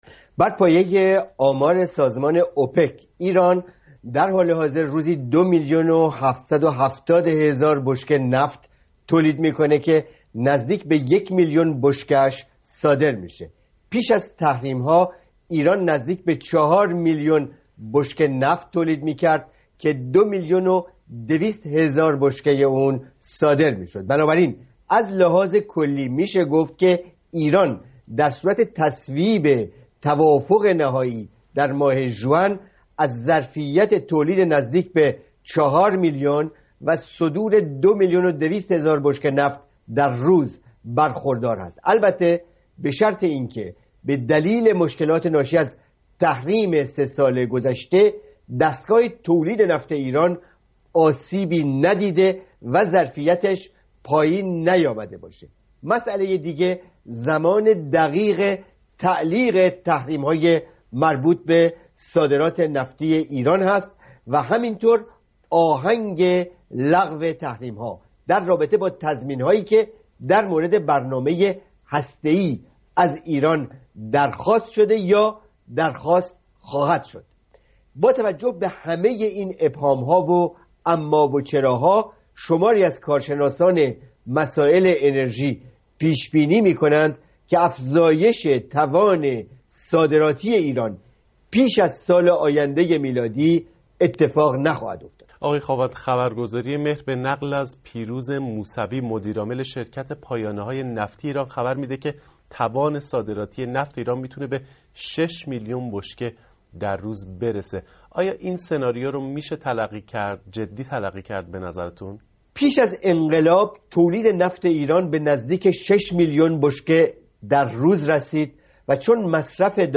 ظرفیت تولید نفت ایران؛ گفت‌وگوی